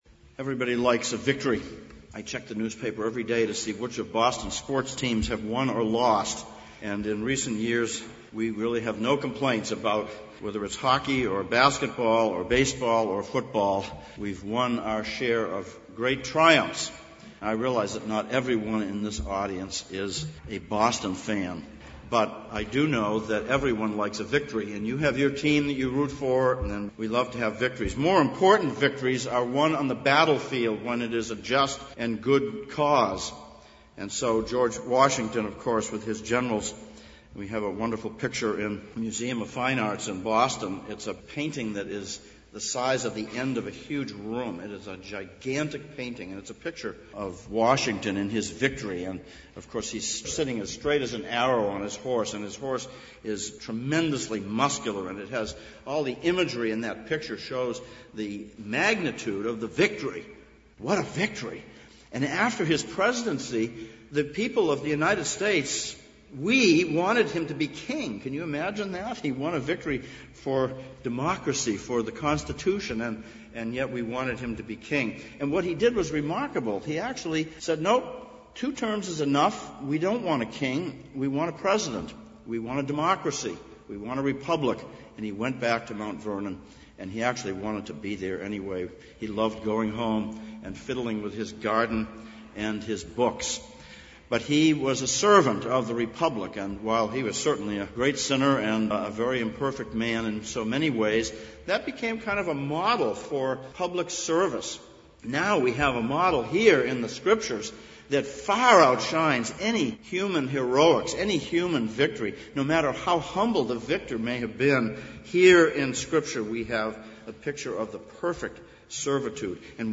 Easter Sermons Passage: Luke 19:28-40, Psalm 118:1-29 Service Type: Sunday Morning « 5.